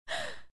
دانلود آهنگ عطسه زن 1 از افکت صوتی انسان و موجودات زنده
دانلود صدای عطسه زن 1 از ساعد نیوز با لینک مستقیم و کیفیت بالا
جلوه های صوتی